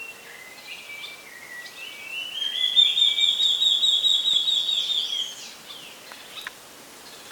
BORRALHARA-ASSOBIADORA
Nome em Inglês: Large-tailed Antshrike
Canto
Local: RPPN Corredeiras do Rio Itajaí, Itaiópolis SC